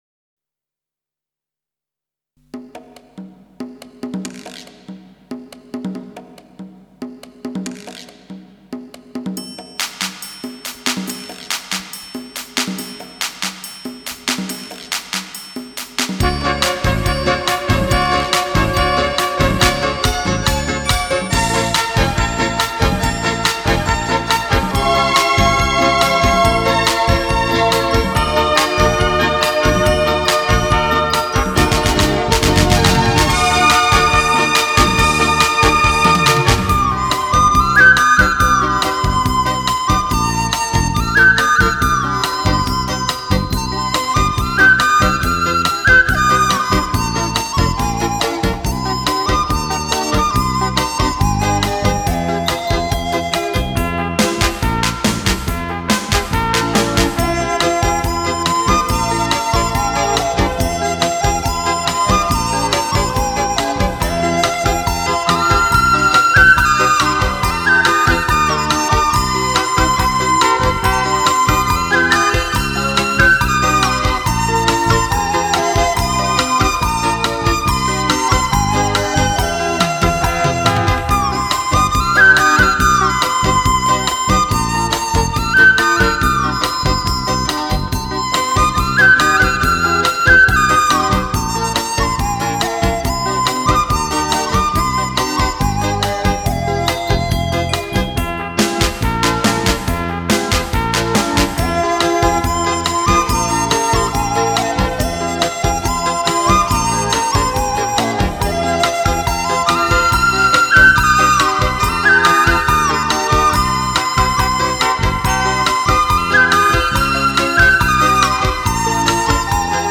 民乐。
笛子